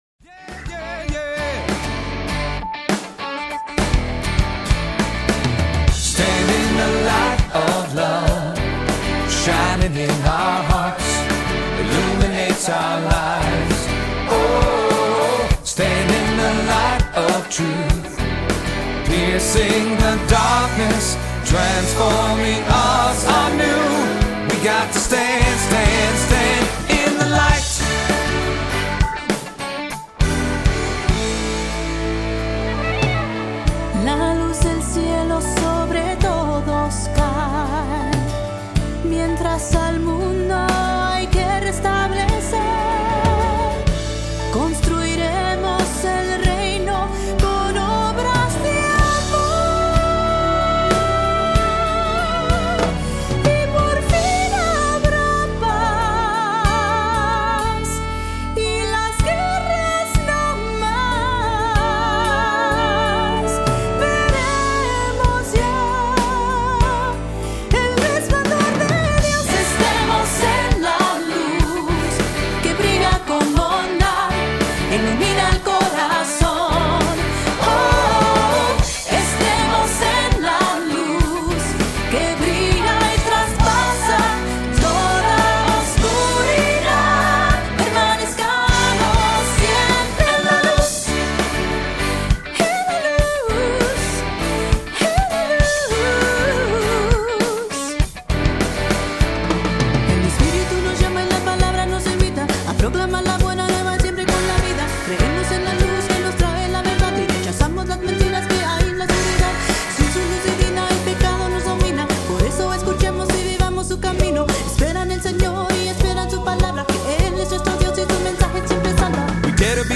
Voicing: Three-part equal; Solo; Assembly